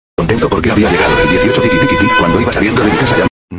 Goofy Ahh Siren Meme